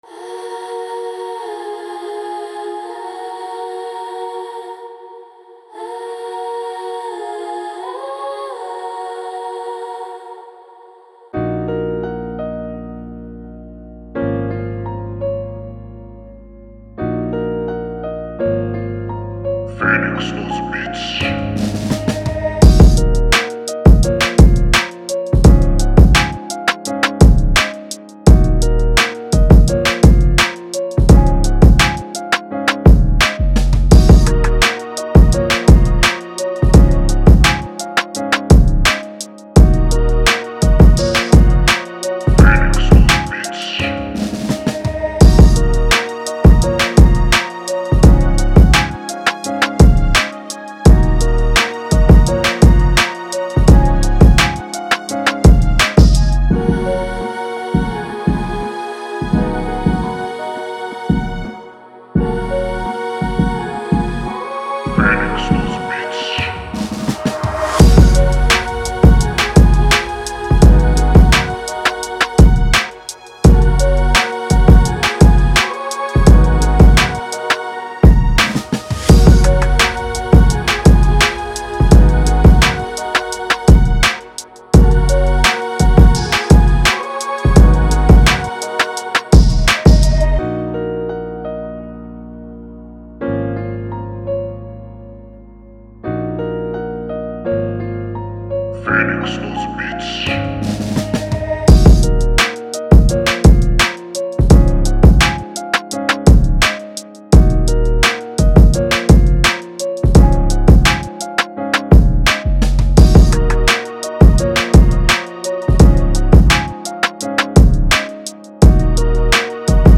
Emotional Sad RNB Instrumental